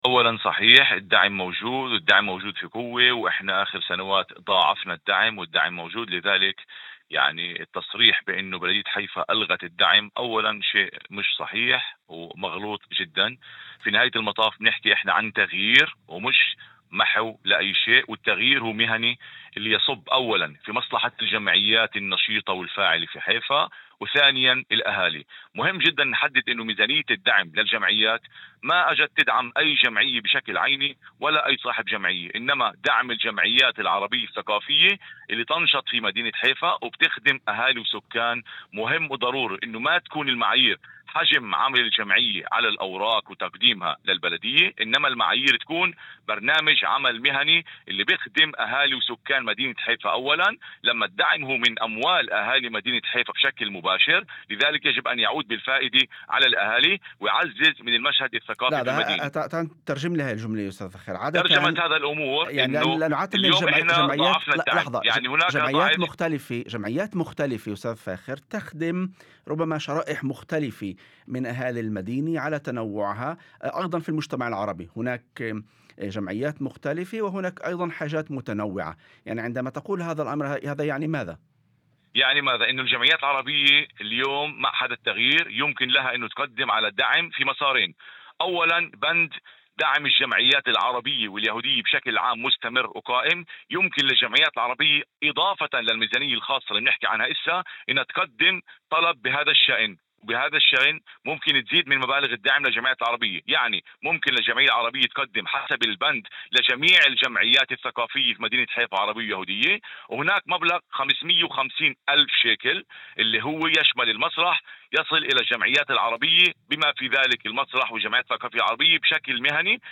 وفي مداخلة هاتفية لبرنامج "يوم جديد"، على إذاعة الشمس، شدد على أن البلدية ضاعفت دعمها للجمعيات خلال السنوات الأخيرة، وأن الميزانيات ما زالت قائمة ومضمونة.